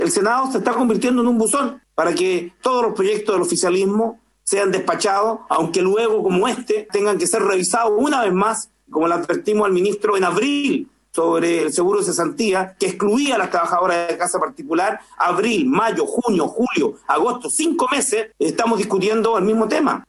Por otra parte, el senador del Partido Progresista, Alejandro Navarro, manifestó su desconfianza respecto al compromiso del Ejecutivo, considerando que la discusión se planteó desde un principio durante la tramitación de la Ley de Protección del Empleo.